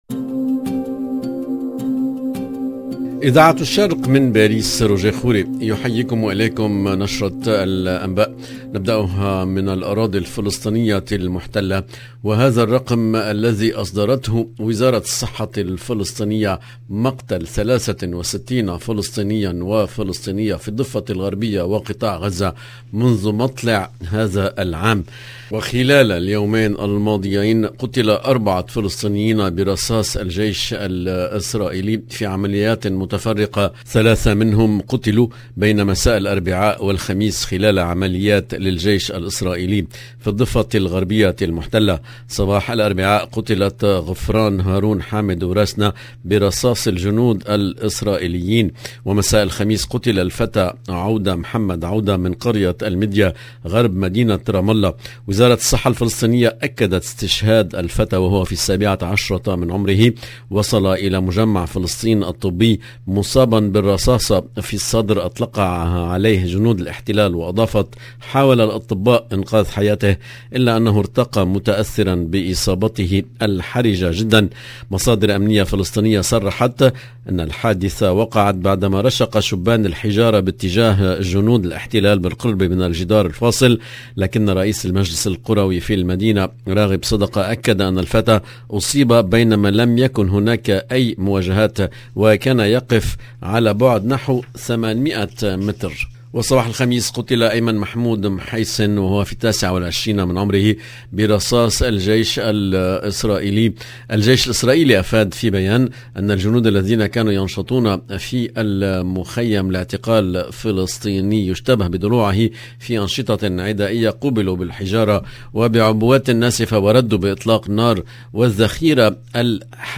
EDITION DU JOURNAL DU SOIR EN LANGUE ARABE DU 3/6/2022